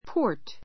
port pɔ́ː r t ポ ー ト 名詞 港; 港町 come into port come into port 入港する a port town a port town 港町 the port of Yokohama the port of Yokohama 港町横浜 ⦣ この of は同格を示す.